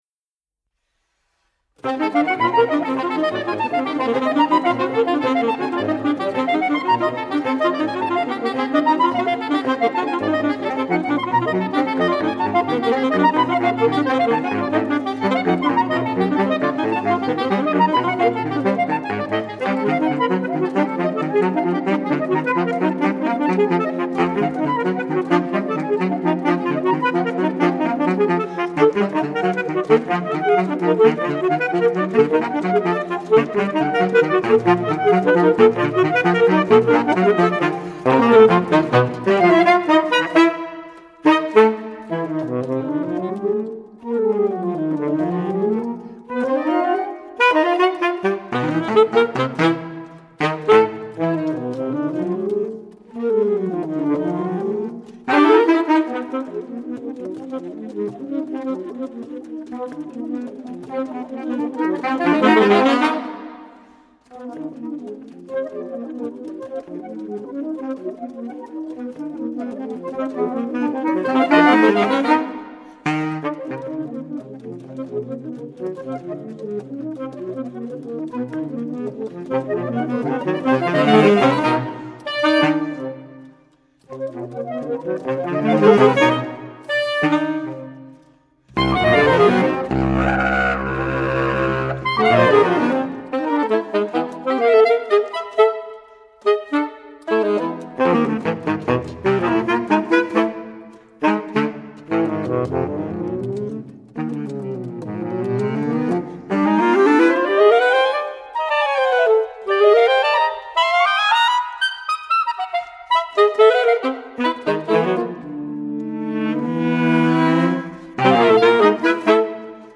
ss, as, ts, bs